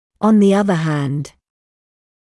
[ɔn ðɪ ‘ʌðə hænd][он зи ‘азэ хэнд]с другой стороны